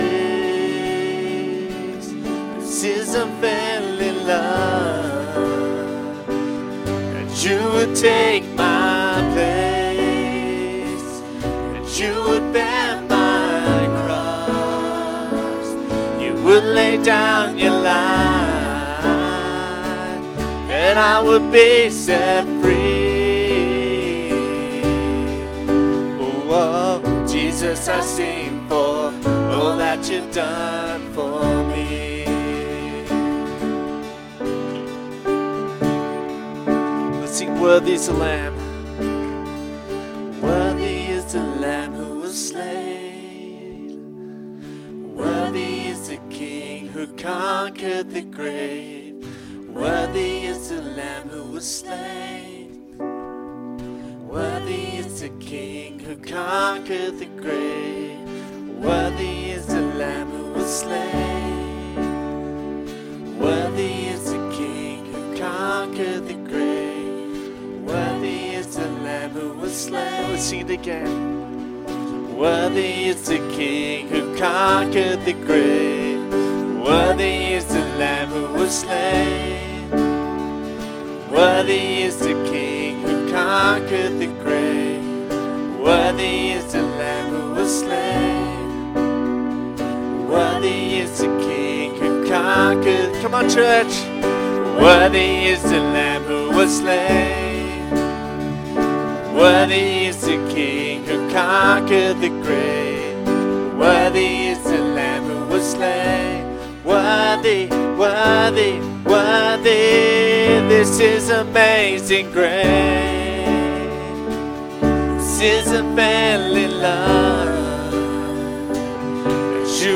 Sunday Service (full service)